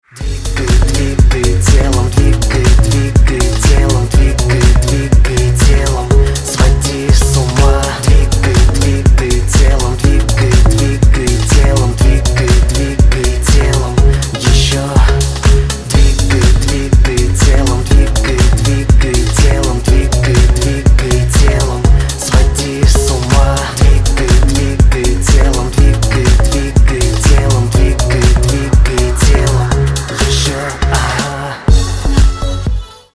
поп
dance